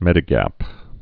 (mĕdĭ-găp)